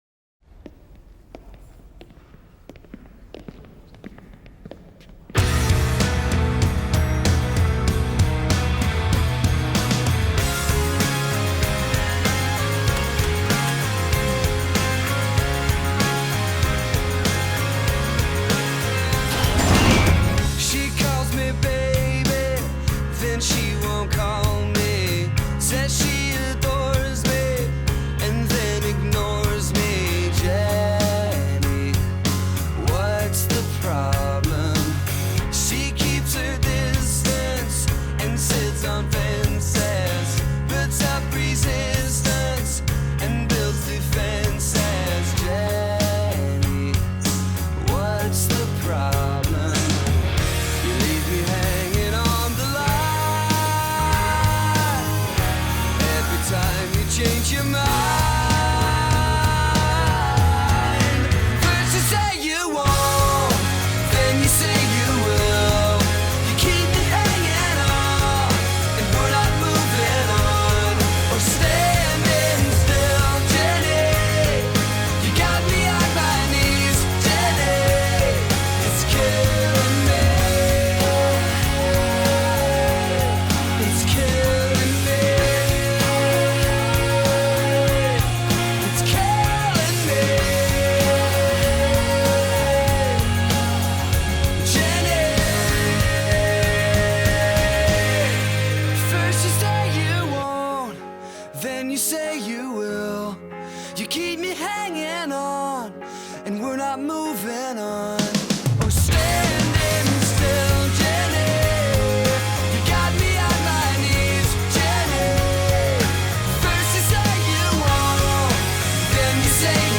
BPM72-96
Audio QualityCut From Video